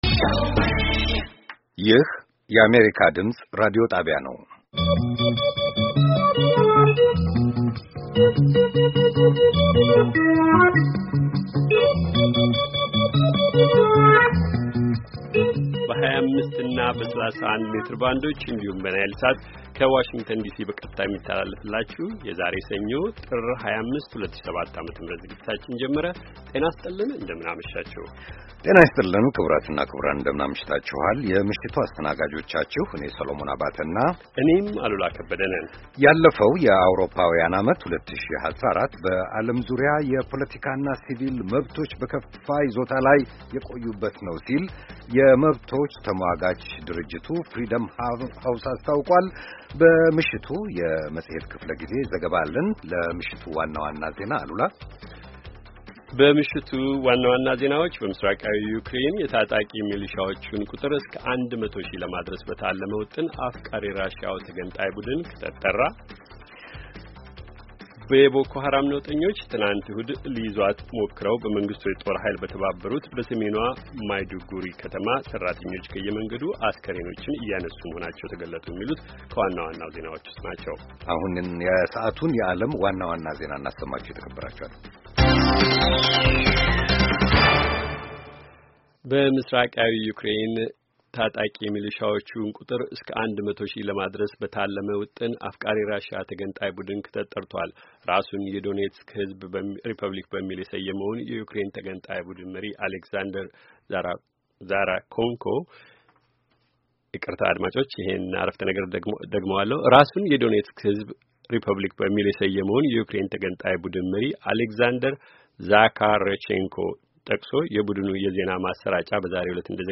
ከምሽቱ ሦስት ሰዓት የአማርኛ ዜና